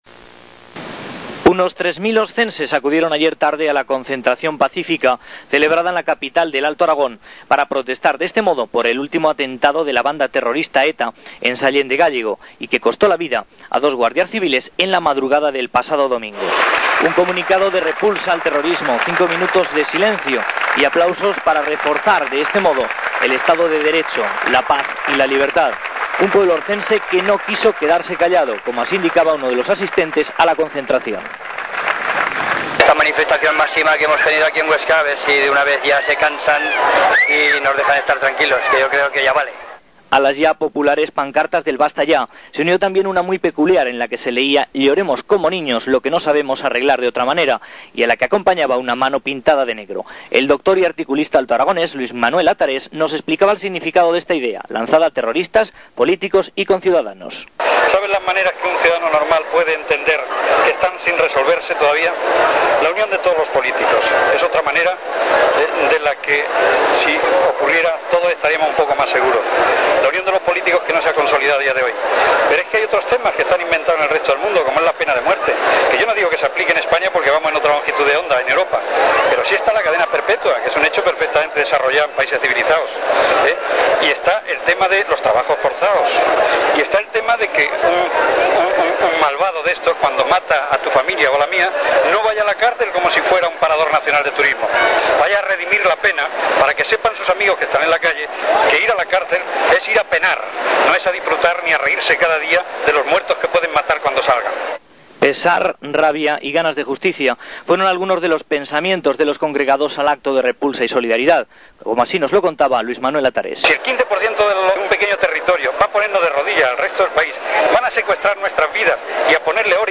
Crónica en M80 Fraga